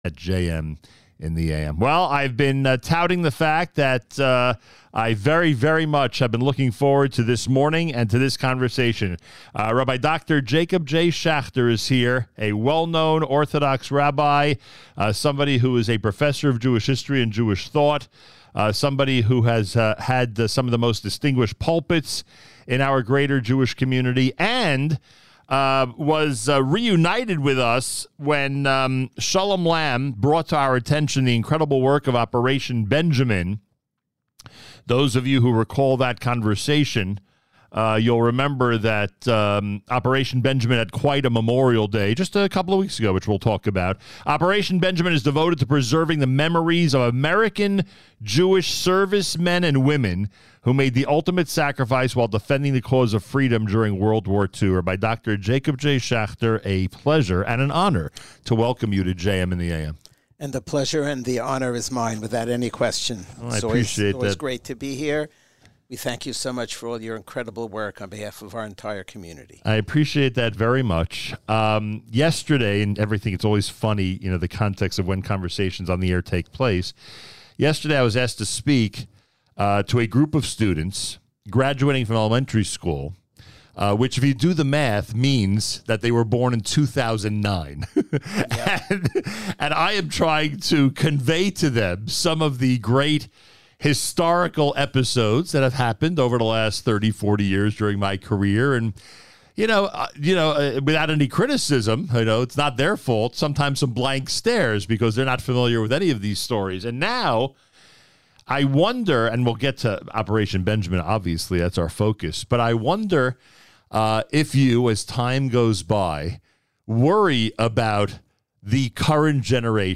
live at JM in the AM this morning to discuss the organization and their recent Memorial Day Mission to France.